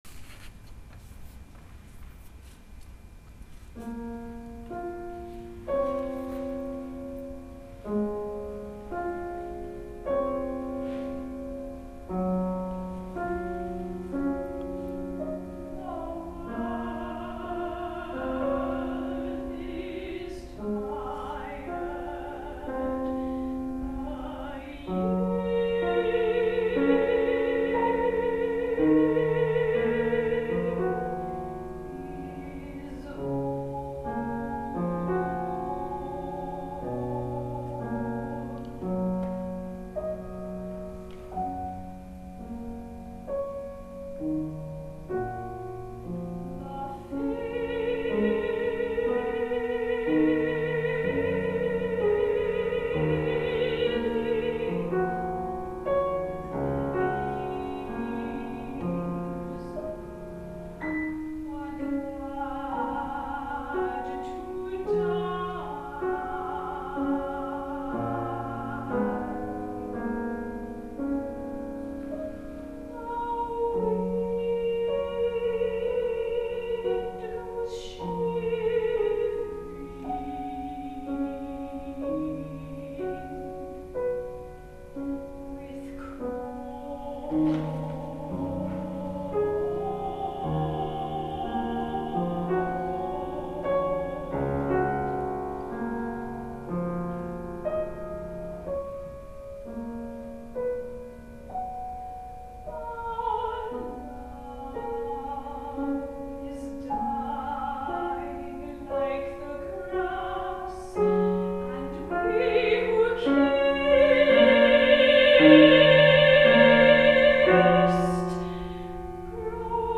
Voice and Piano (High and Medium version)